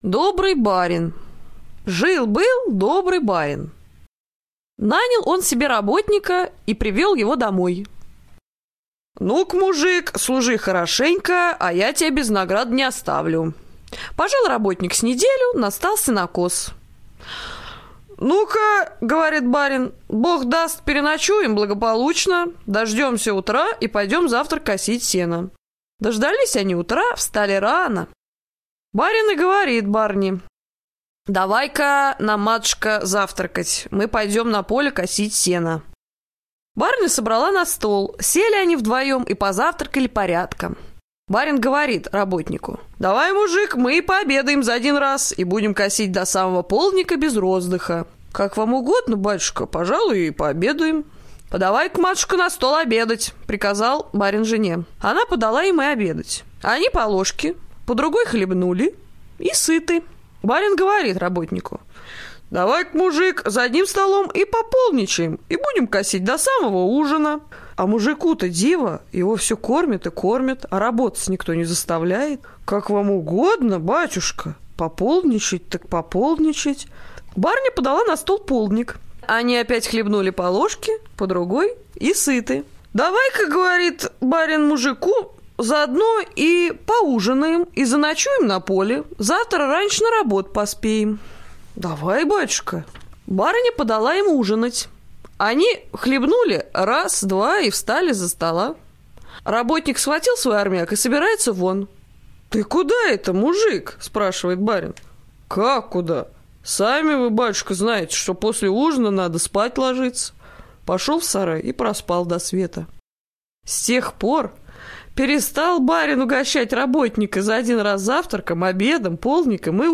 Добрый барин - латышская аудиосказка - слушать онлайн